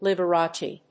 /ˌlɪˌbɝˈɑˌtʃi(米国英語), ˌlɪˌbɜ:ˈɑ:ˌtʃi:(英国英語)/